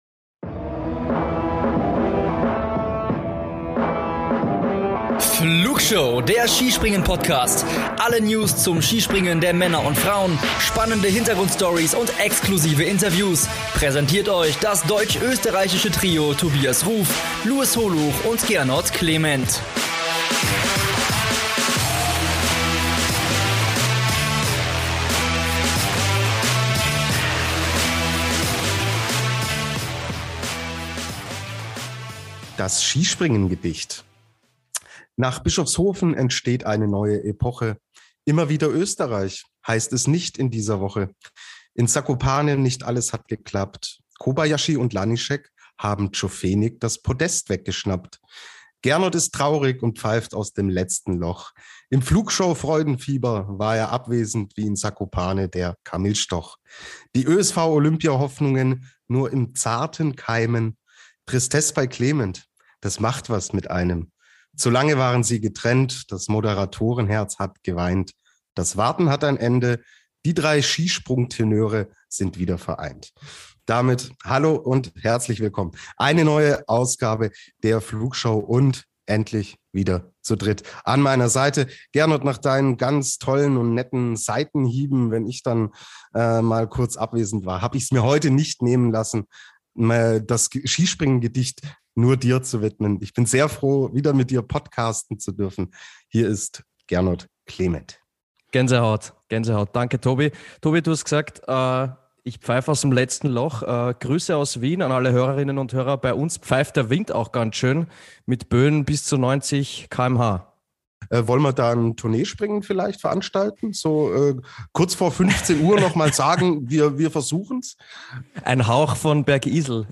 Außerdem diskutiert das deutsch-österreichische Trio, wer vor allem bei DSV und ÖSV im Flieger nach Peking sitzen wird.